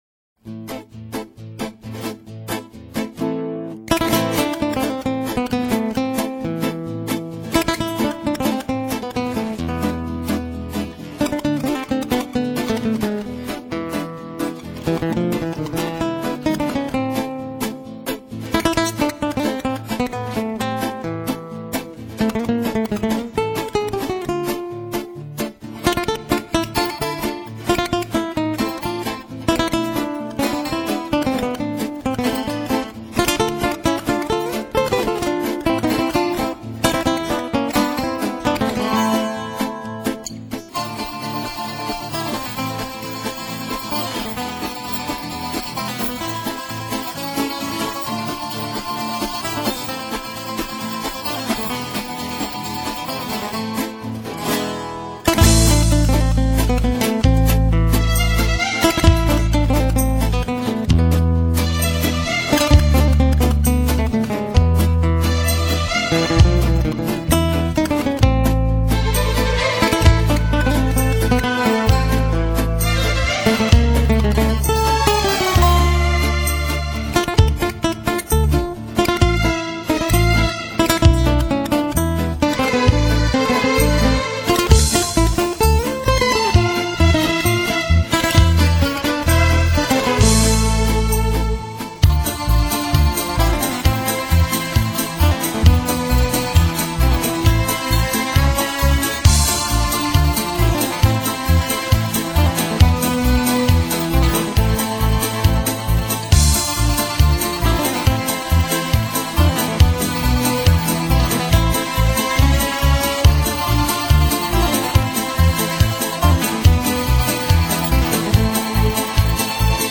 [2007-6-7]热情奔放的南美吉他